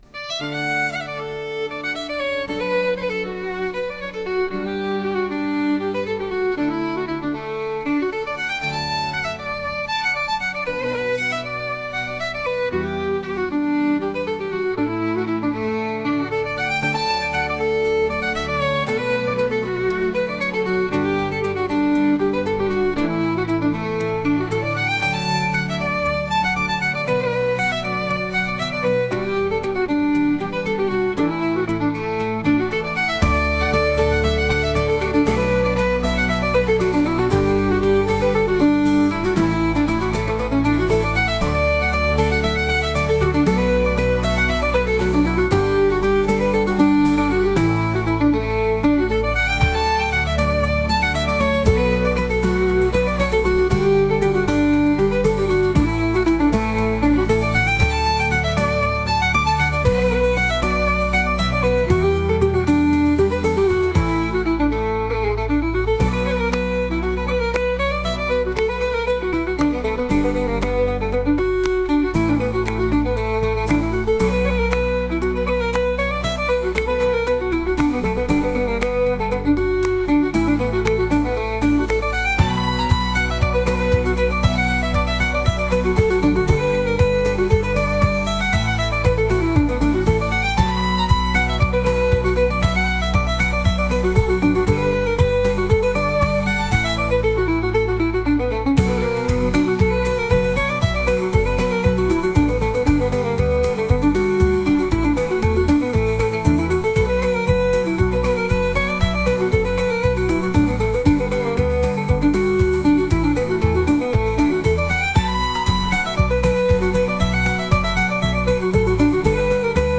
街道を進んでいくようなケルト曲です 音楽素材（MP3）ファイルのダウンロード、ご利用の前に必ず下記項目をご確認ください。